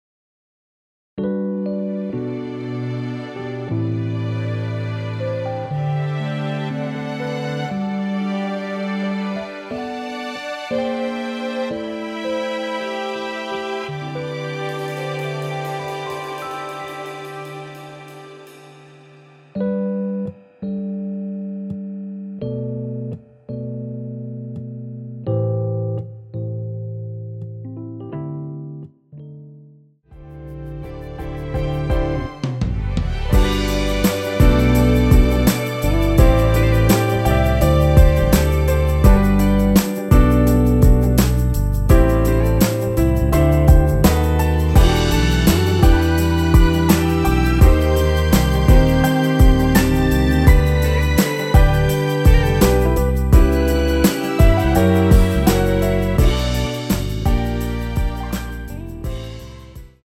여성분들이 부르실수 있는 키로 제작 하였습니다.
원키에서(+5)올린 MR입니다.
앞부분30초, 뒷부분30초씩 편집해서 올려 드리고 있습니다.